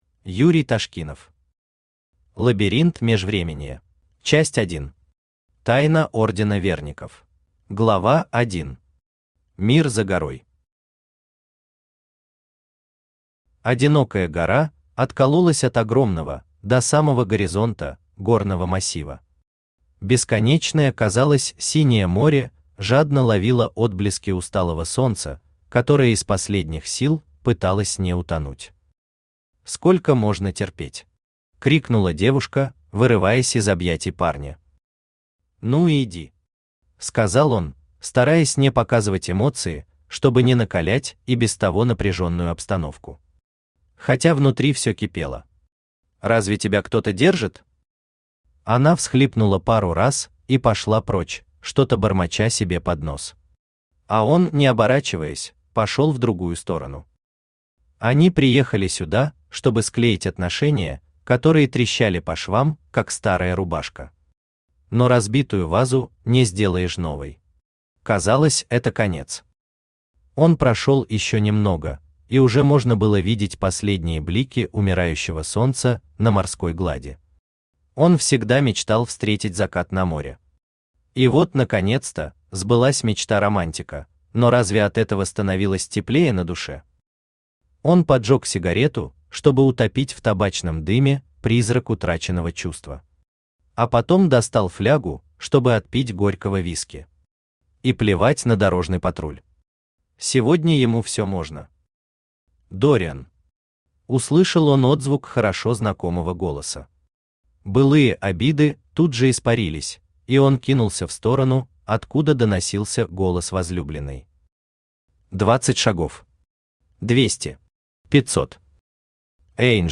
Аудиокнига Лабиринт Межвременья | Библиотека аудиокниг
Aудиокнига Лабиринт Межвременья Автор Юрий Андреевич Ташкинов Читает аудиокнигу Авточтец ЛитРес.